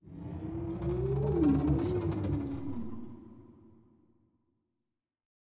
Minecraft Version Minecraft Version snapshot Latest Release | Latest Snapshot snapshot / assets / minecraft / sounds / ambient / nether / crimson_forest / shroom3.ogg Compare With Compare With Latest Release | Latest Snapshot